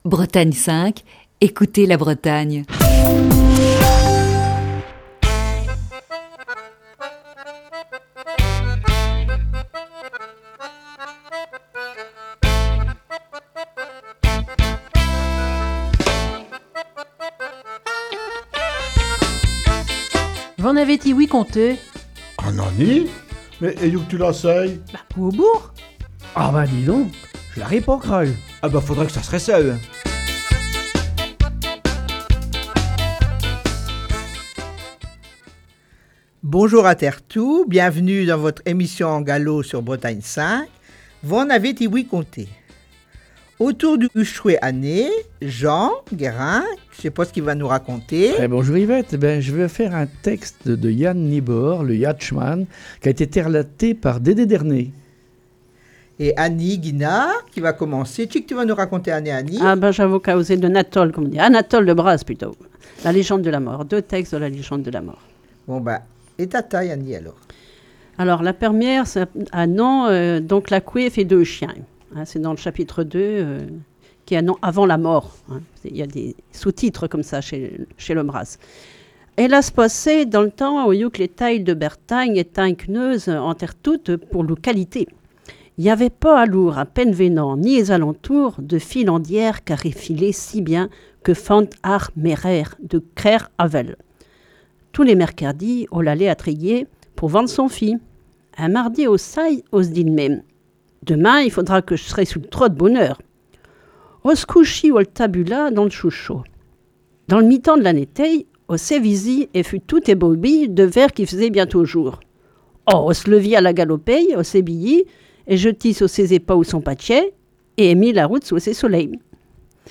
La troupe de V'en avez ti-ouï conté ? nous propose une série d'émissions basée sur "La Nuit de la Lecture", qui se déroulait le 18 janvier dernier à la Bibliothèque de Lamballe.